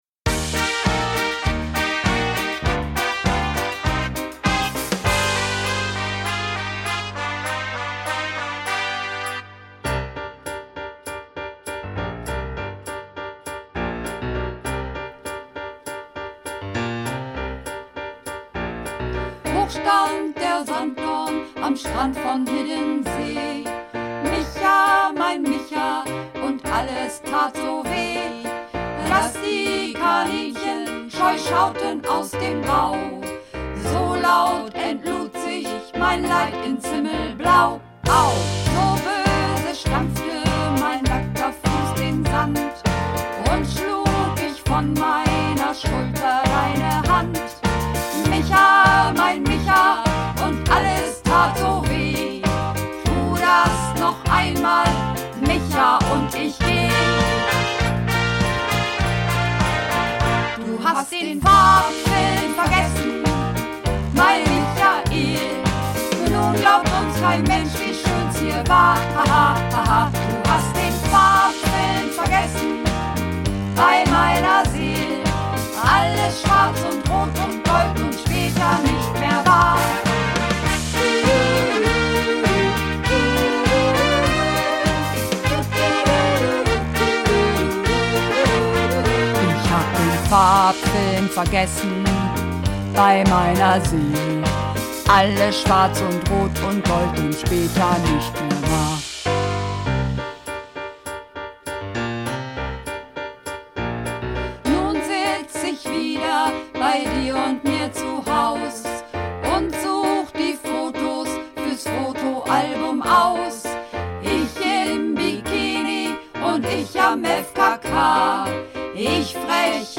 Mehrstimmig